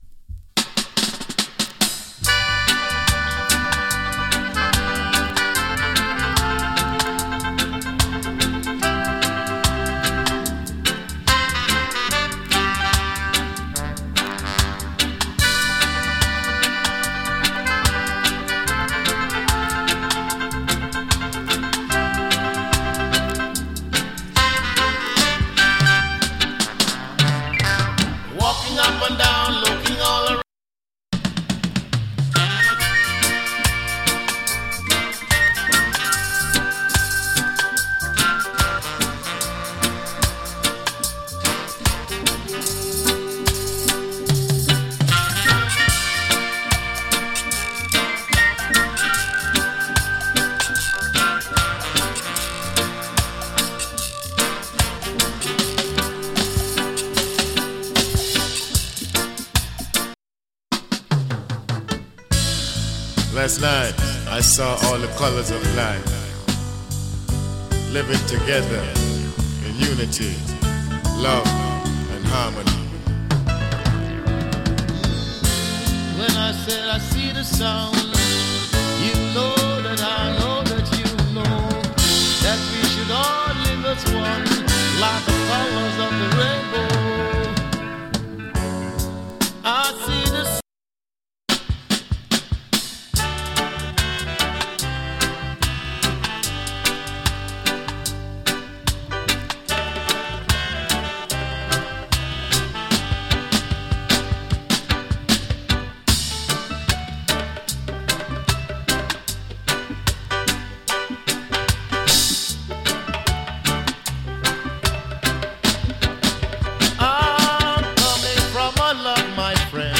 チリ、ジリノイズわずかに有り。
79年リリースの ROOTS ROCK ALBUM !